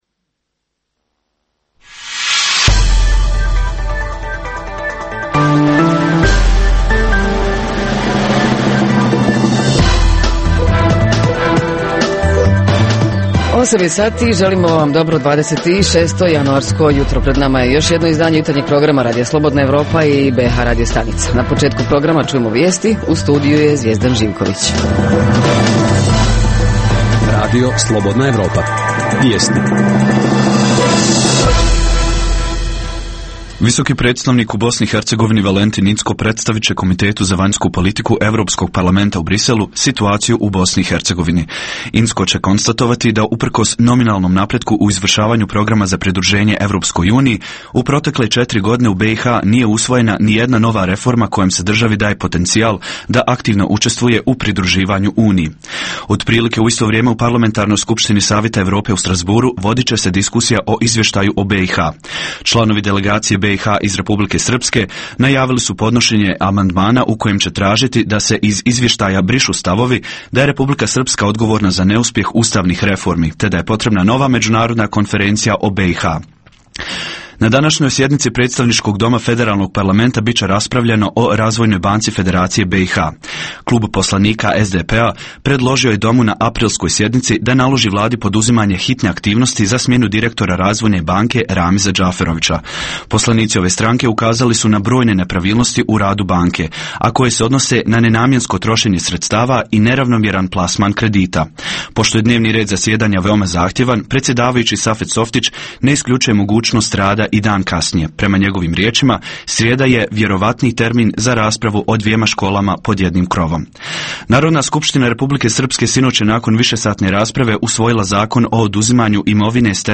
O ljudskim pravima Roma - da li postoji neki napredak kada je u pitanju zapošljavanje, stanovanje, edukacija Redovna rubrika Radija 27 utorkom je "Svijet interneta". Redovni sadržaji jutarnjeg programa za BiH su i vijesti i muzika.